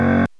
wrong.mp3